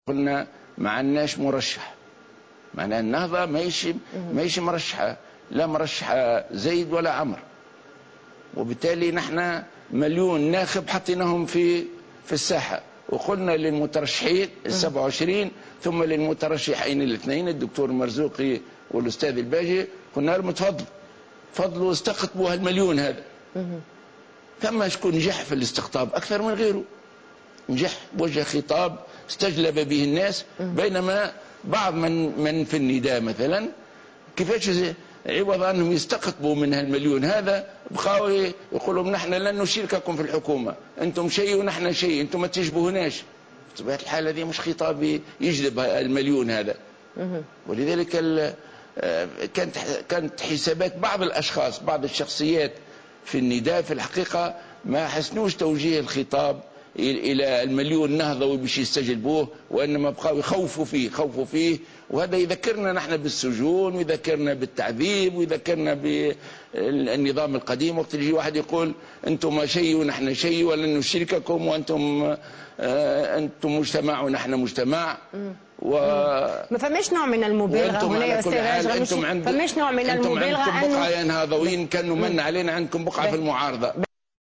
أكد رئيس حركة النهضة راشد الغنوشي في حوار مع قناة نسمة اليوم الثلاثاء ان الناخب التونسي هو من يقرّر من الأصلح لتونس مضيفا ان بيان مجلس الشورى و موقف حركة النهضة هو الحيّاد.